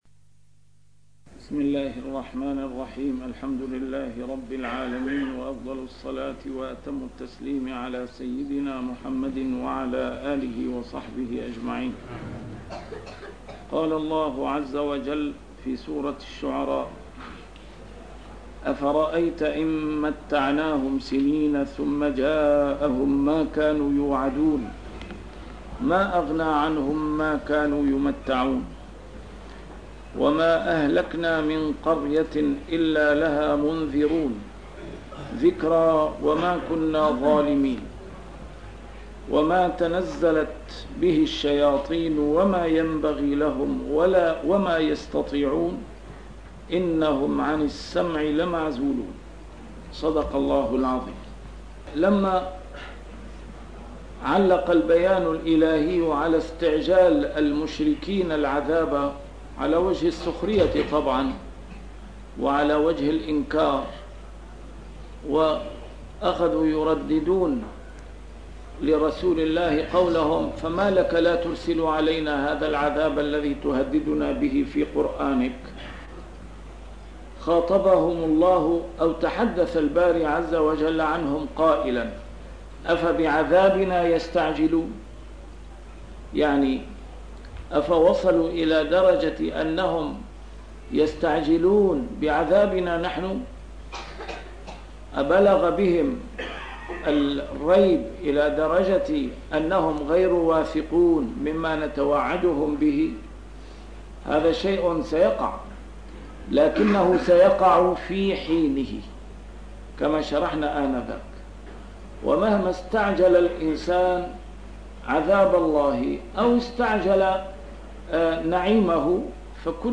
A MARTYR SCHOLAR: IMAM MUHAMMAD SAEED RAMADAN AL-BOUTI - الدروس العلمية - تفسير القرآن الكريم - تسجيل قديم - الدرس 241: الشعراء 205-212